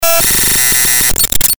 Gemafreie Sounds: Kratzen
mf_SE-989-code_digital_hard_1.mp3